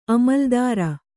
♪ amaldāra